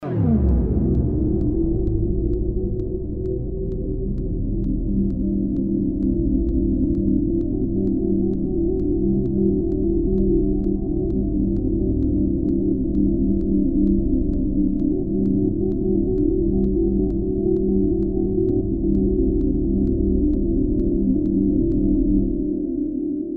Freeze time effect